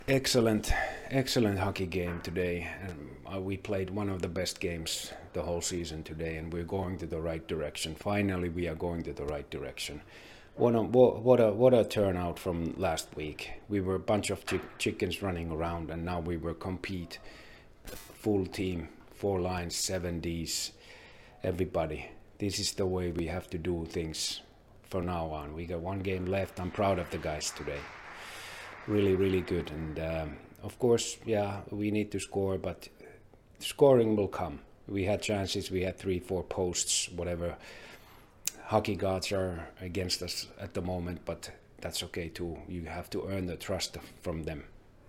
Post Game-Kommentar